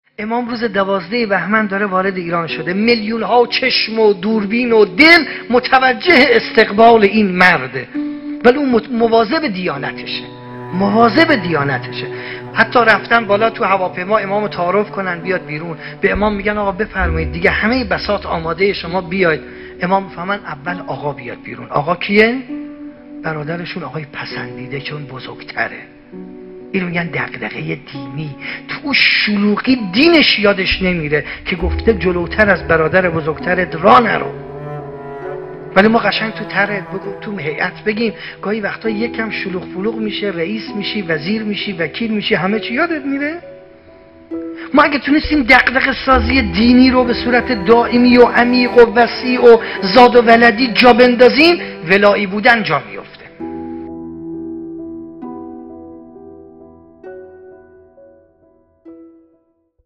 گزیده ای از سخنرانی
نخستین همایش هیأت های محوری و برگزیده کشور